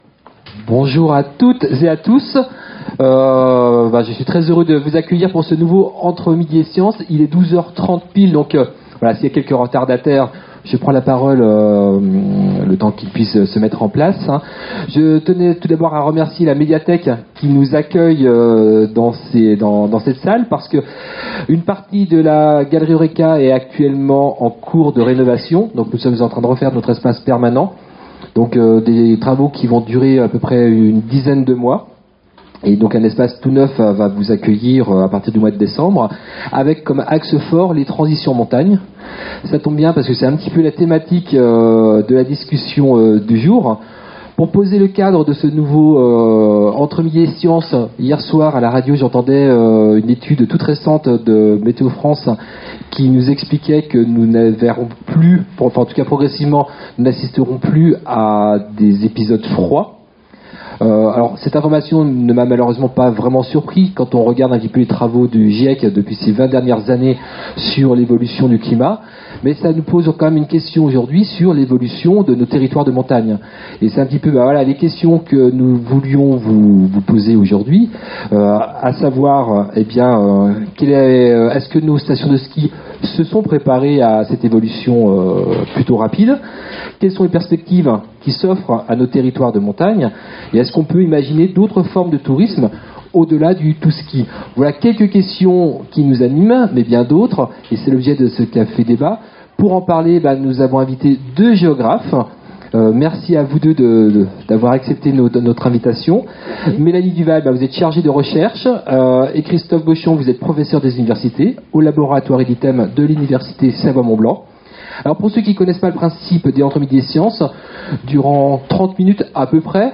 Une fois par mois, à la Galerie Eurêka, venez rencontrer des spécialistes, poser vos questions et débattre avec eux lors des rendez-vous « Entre midi & science ».
Ecoutez le café-débat et retrouvez toutes les questions du public sur l'enregistrement audio .